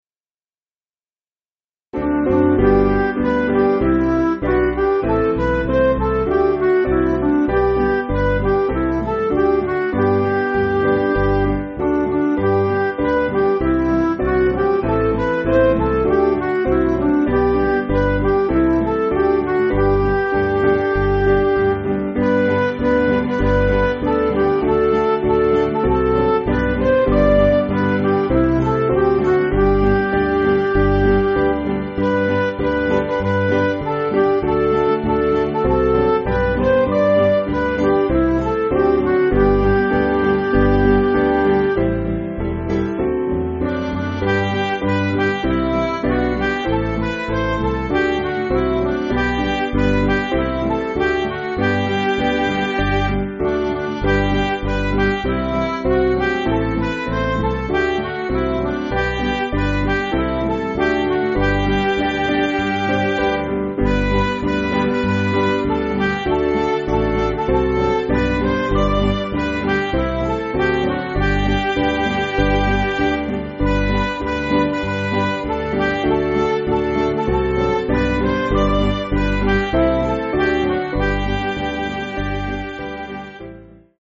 Piano & Instrumental
(CM)   3/G